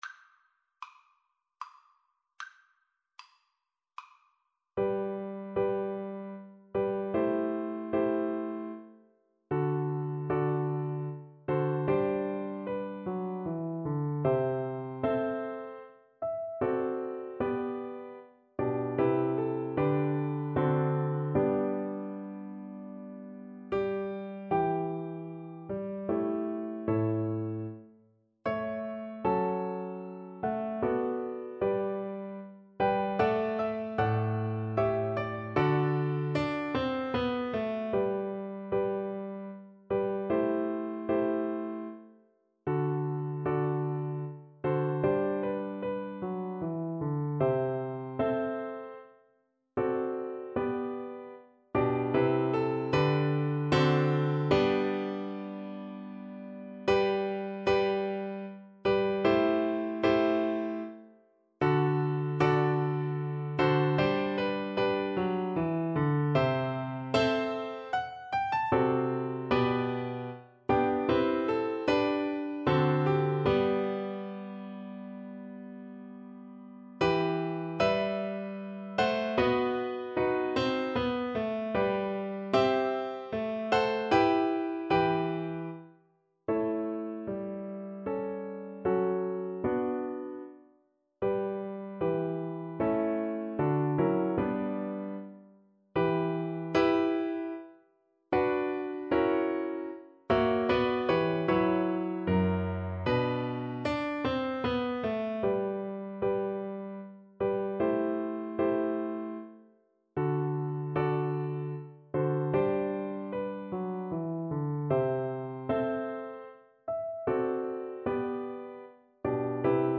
3/4 (View more 3/4 Music)
G major (Sounding Pitch) (View more G major Music for Viola )
Larghetto = 76
Classical (View more Classical Viola Music)